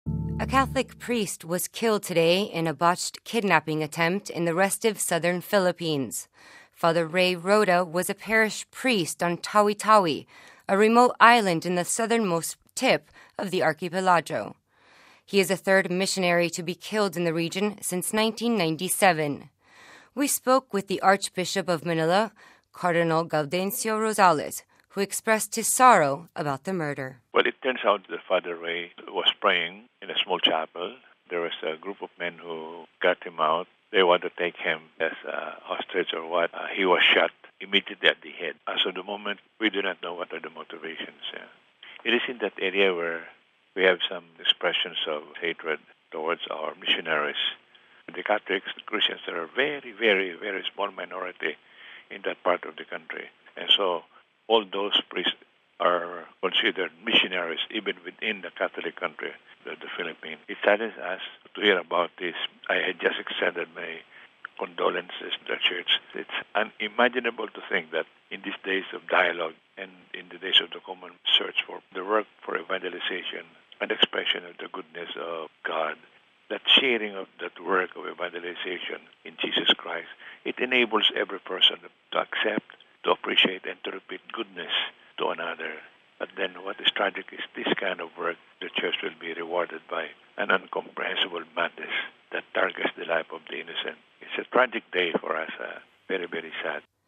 Home Archivio 2008-01-16 18:34:18 A Missionary is Killed in the Philippines (16 Jan 08 – RV) A Catholic priest was killed today in a botched kidnapping attempt in the restive southern Philippines. We spoke to the Archbishop of Manila...